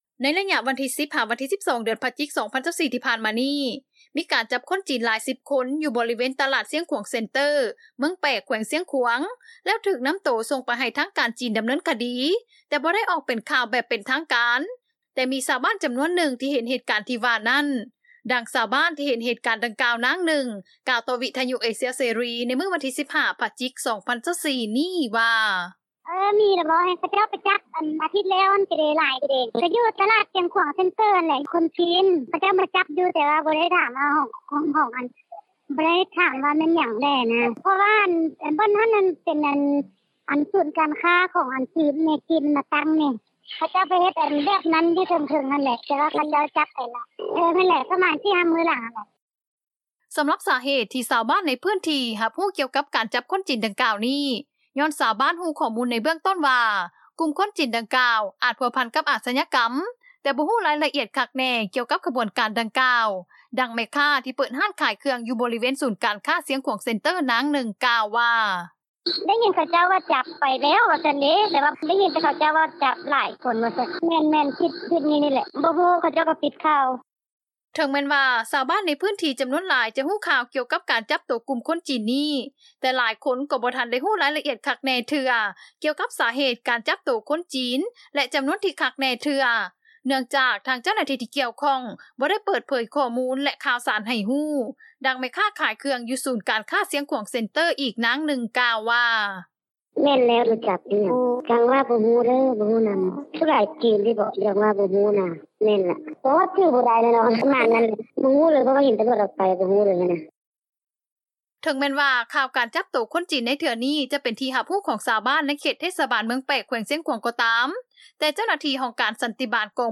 ສໍາລັບສາເຫດ ທີ່ຊາວບ້ານໃນພື້ນທີ່ ຮັບຮູ້ກ່ຽວກັບການຈັບຄົນຈີນດັ່ງກ່າວນີ້ ຍ້ອນຊາວບ້ານຮູ້ຂໍ້ມູນ ໃນເບື້ອງຕົ້ນວ່າ ກຸ່ມຄົນຈີນດັ່ງກ່າວ ອາດພົວພັນ ກັບອາດຊະຍາກໍາ ແຕ່ບໍ່ຮູ້ລາຍລະອຽດຄັກແນ່ ກ່ຽວກັບຂະບວນການດັ່ງກ່າວ, ດັ່ງແມ່ຄ້າ ທີ່ເປີດຮ້ານຂາຍເຄື່ອງ ຢູ່ບໍລິເວນສູນການຄ້າ ຊຽງຂວາງເຊັນເຕີ້ ນາງໜຶ່ງ ກ່າວວ່າ: